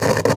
radio_tv_electronic_static_18.wav